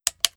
FlashlightSwitchOn.wav